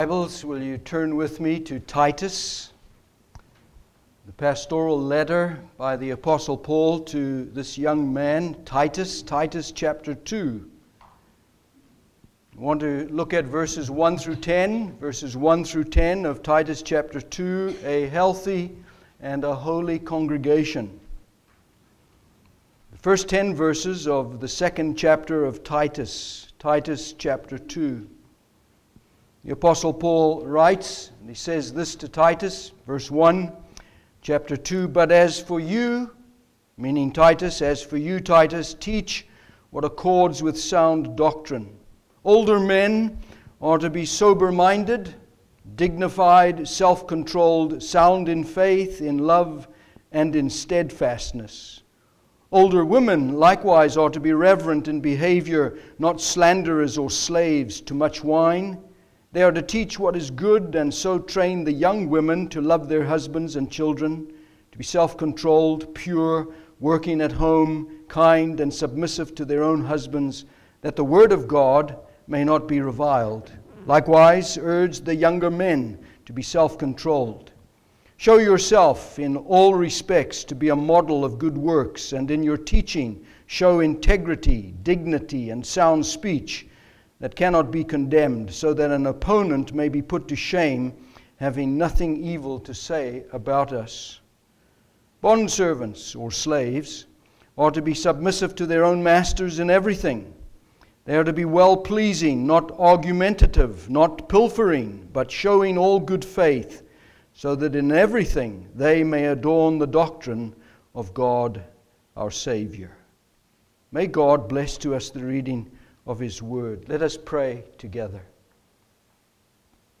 Sermon – Establishing the Church